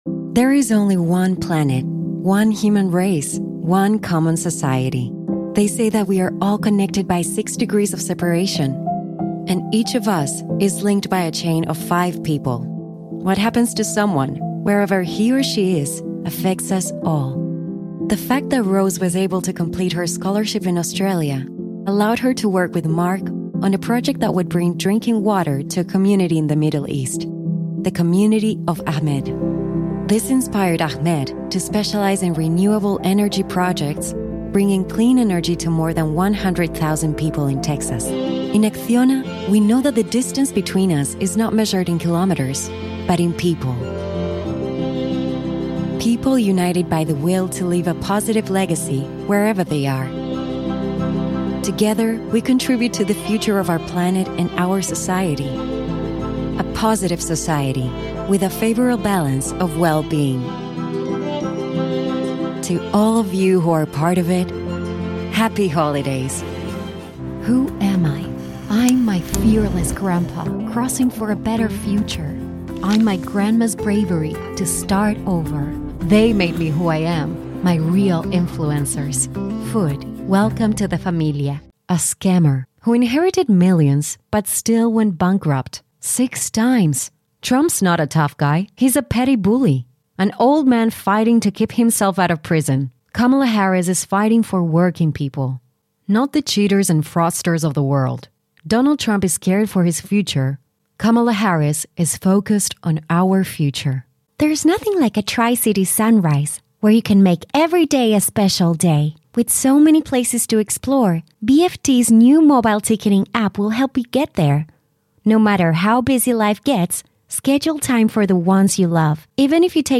English with Spanish accent